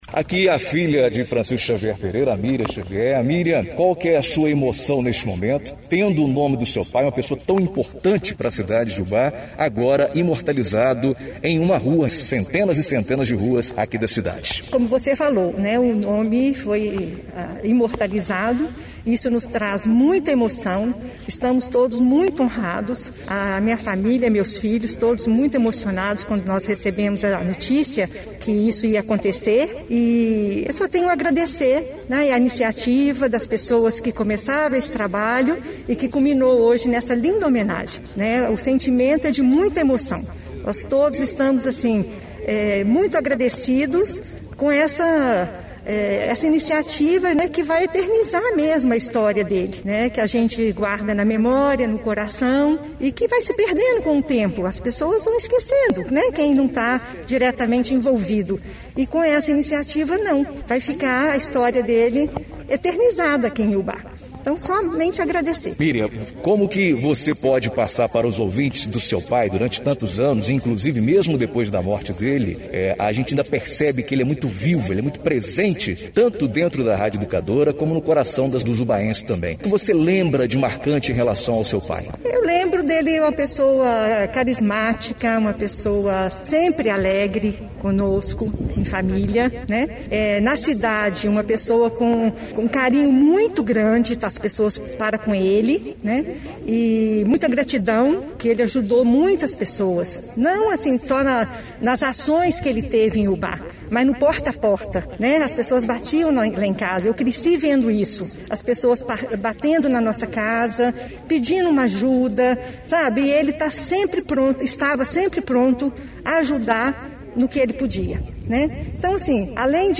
Entrevista exibida no jornal em dia com à notícia na Rádio Educadora AM/FM